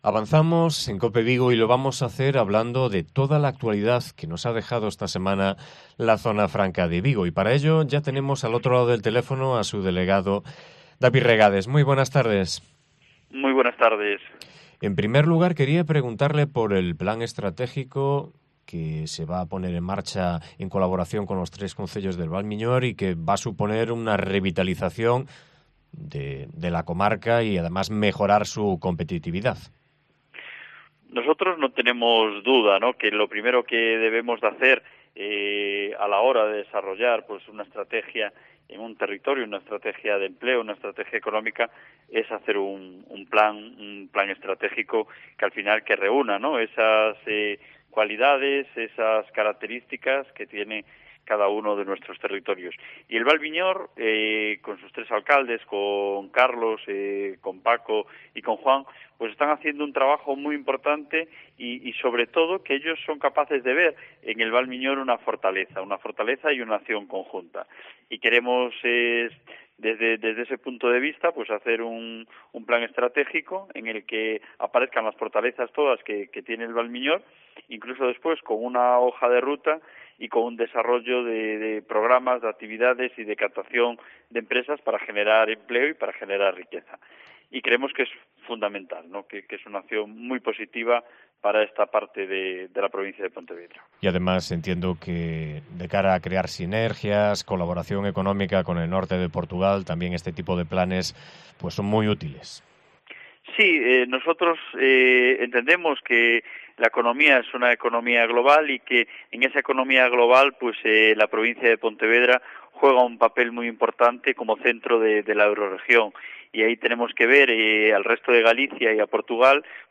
Entrevista a David Regades, delegado de Zona Franca de Vigo